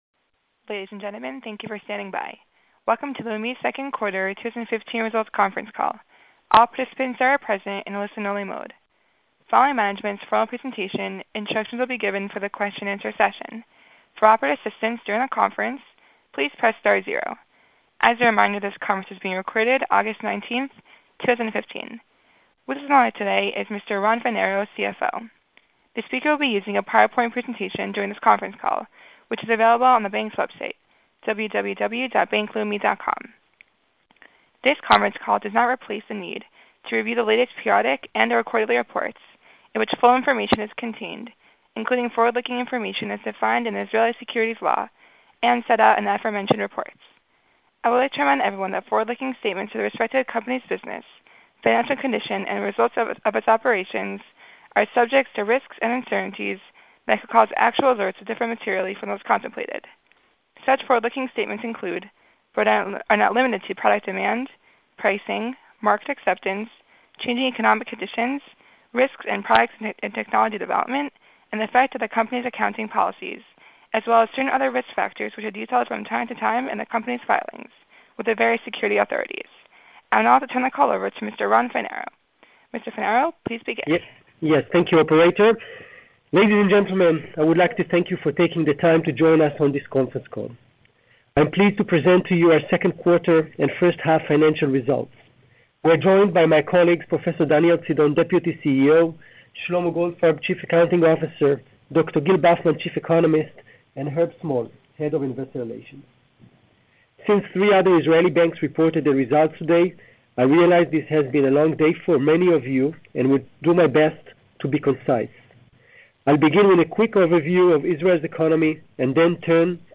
Investor Presentation and Conference Call